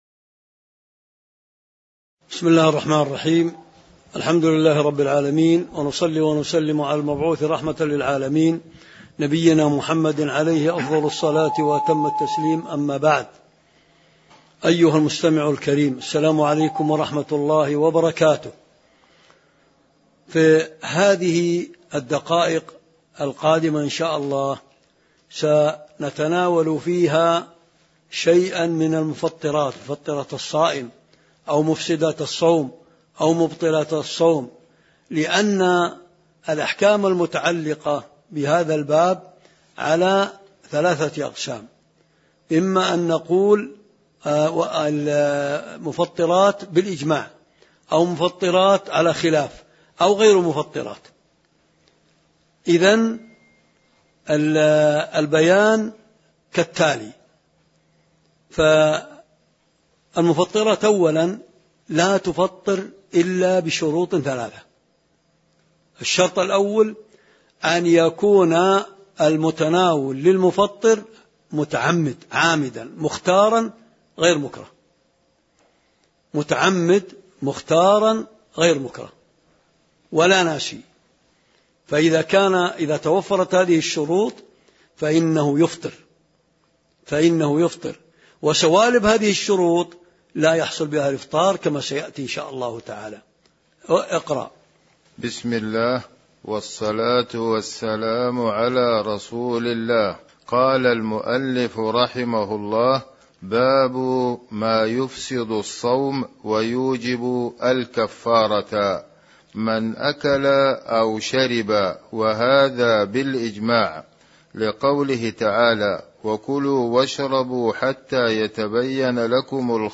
تاريخ النشر ٦ رمضان ١٤٤٢ هـ المكان: المسجد النبوي الشيخ